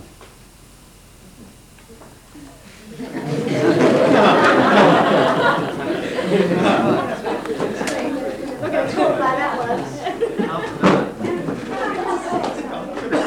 You’re going to have a very serious struggle with low-level noisy lecture and overloaded laughter.
OK, that was the laughter.
Sorry - in shortening the sample to enable easy upload (in a hurry and didn’t check it) I removed the speech which preceded the laughter.